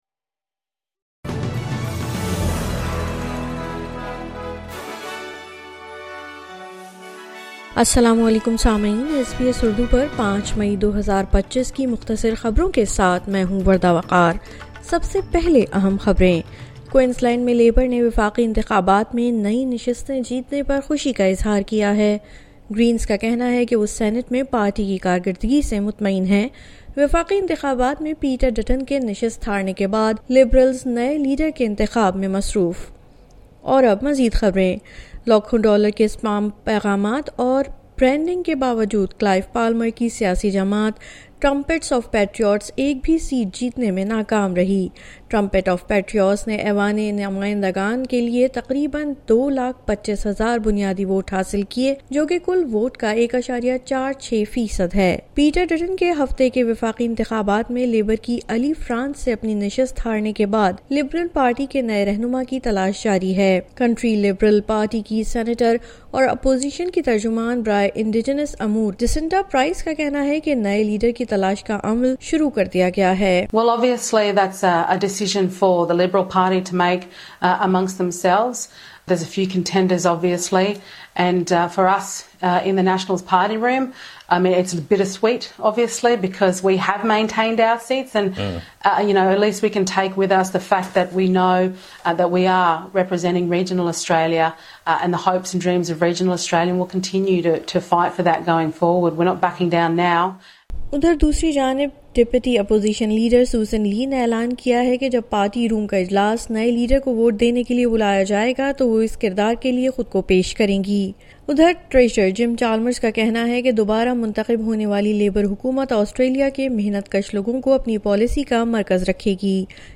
مختصر خبریں: پیر 05 مئی 2025